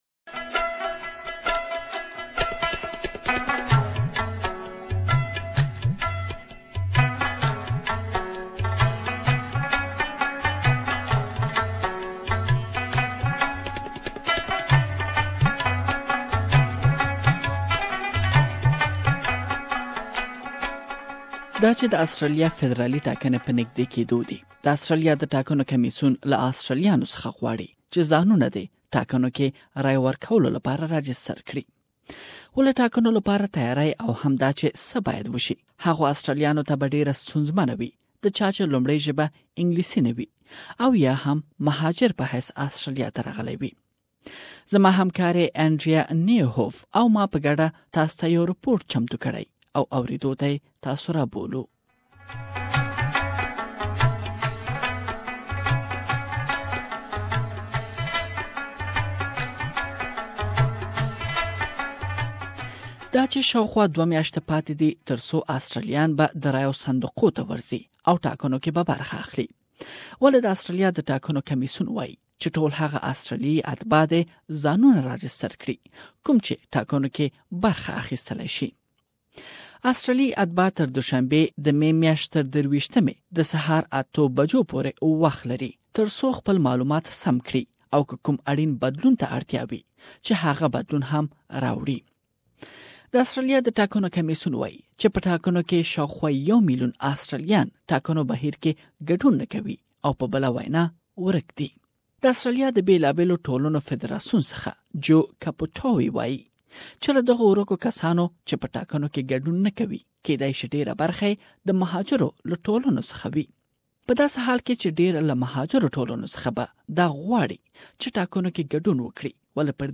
With an upcoming federal election, the Australian Electoral Commission is reminding people to ensure they're registered to vote. But, for many people from migrant and non-English speaking backgrounds, it's not that easy, We have prepared a detailed report that you can listen to it here.